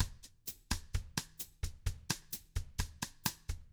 129BOSSAF1-R.wav